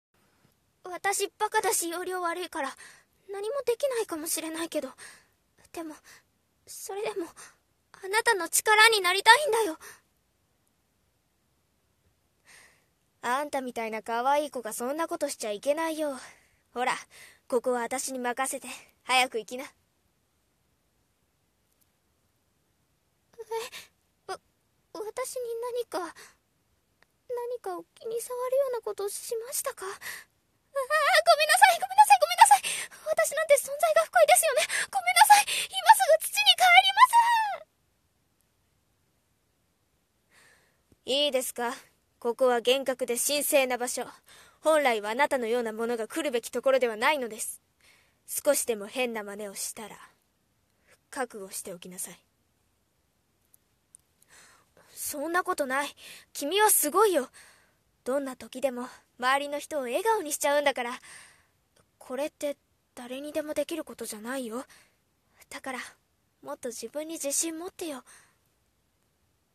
【声劇】ボイスサンプル(女の子Ver.)